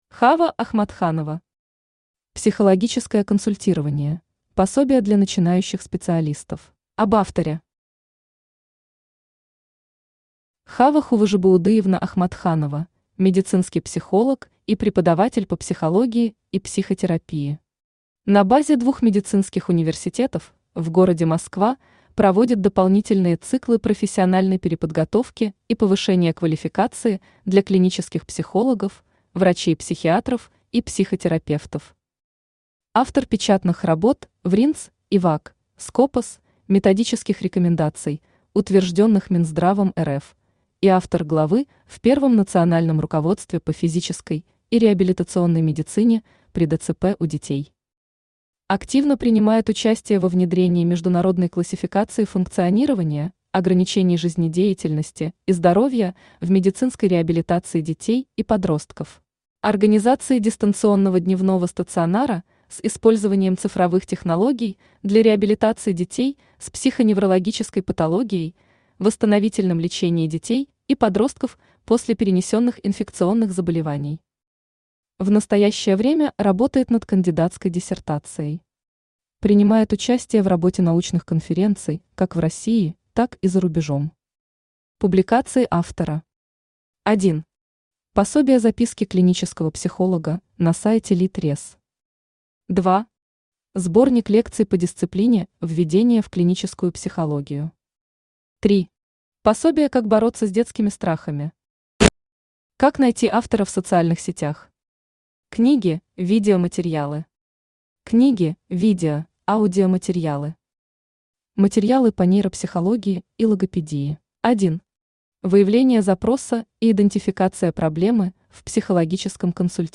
Аудиокнига Психологическое консультирование: пособие для начинающих специалистов | Библиотека аудиокниг
Aудиокнига Психологическое консультирование: пособие для начинающих специалистов Автор Хава Хуважибаудыевна Ахматханова Читает аудиокнигу Авточтец ЛитРес.